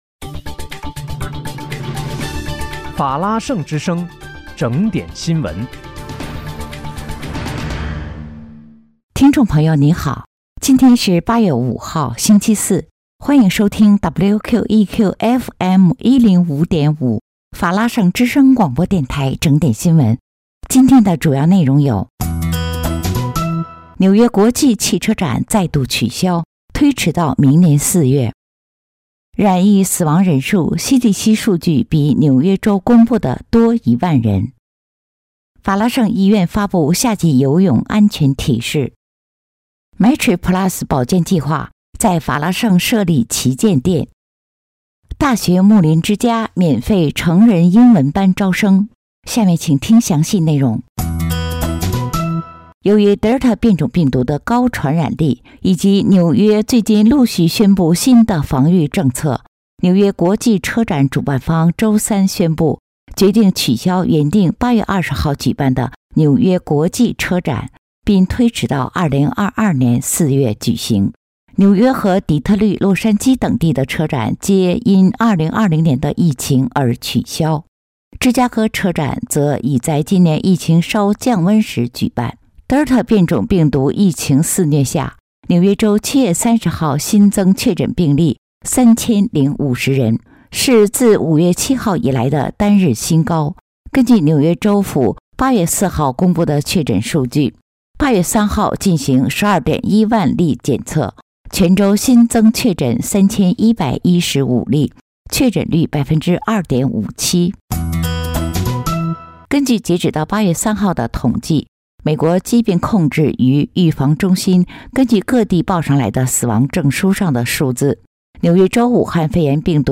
8月5日(星期四）纽约整点新闻